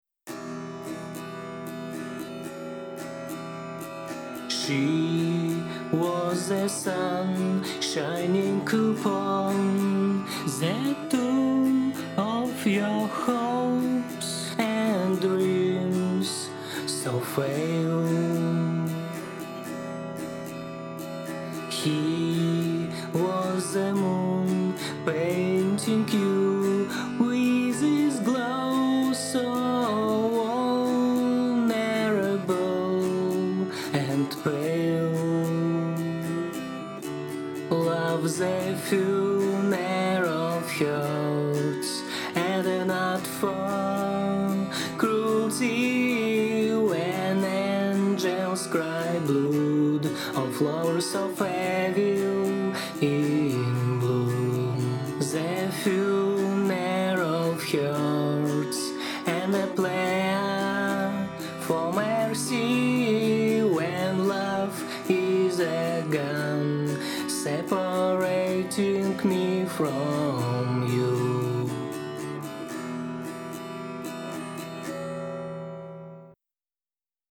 Сведение акустики и вокала
Просто не хотелось портить столь самобытный и в каком-то смысле аутентичный вокал неким аутотюном.